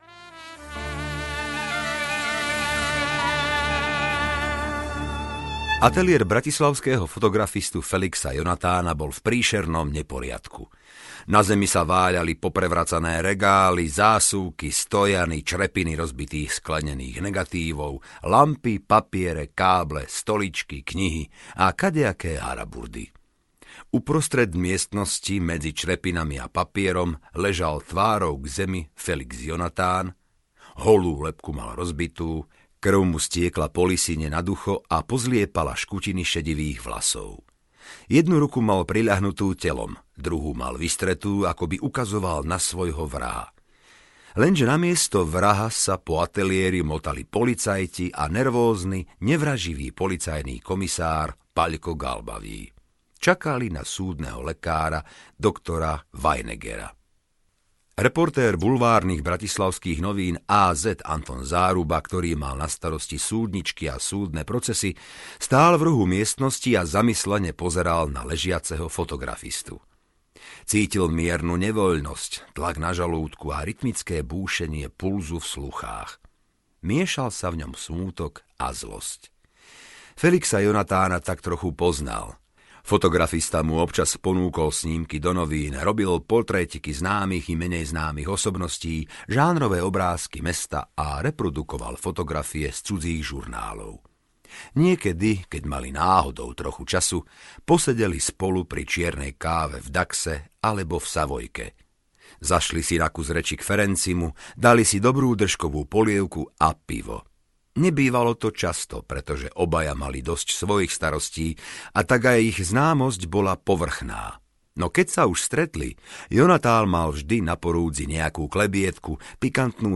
Kain, kde je tvoj brat Ábel? audiokniha
Ukázka z knihy